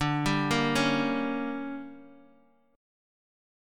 DM7sus4#5 Chord
Listen to DM7sus4#5 strummed